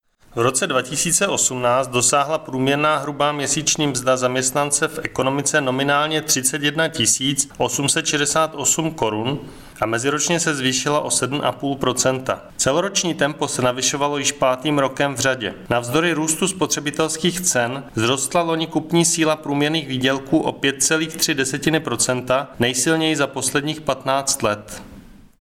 Vyjádření Marka Rojíčka, předsedy ČSÚ, soubor ve formátu MP3, 994.94 kB